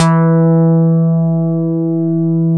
Index of /90_sSampleCDs/Roland L-CDX-01/BS _Synth Bass 1/BS _MIDI Bass